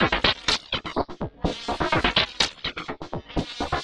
tx_perc_125_digiflange2.wav